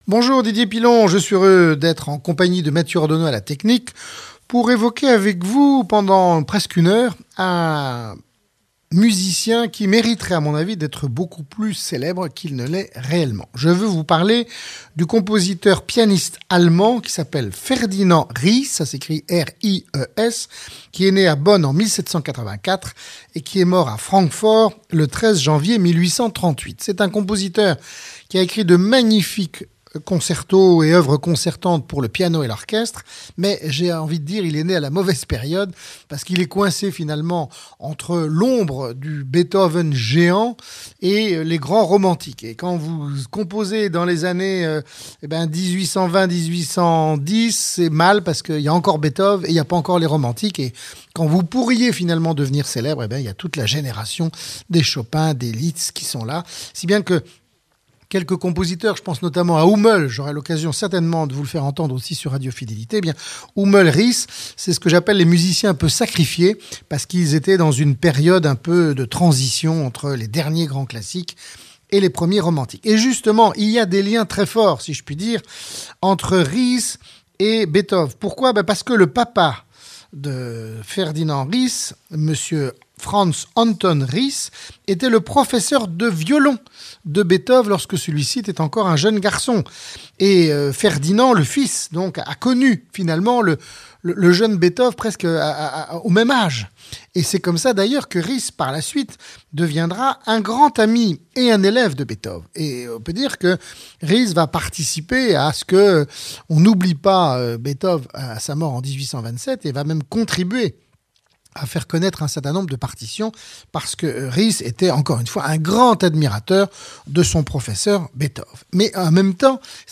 DP-Ferdinand Ries - Concertos pour piano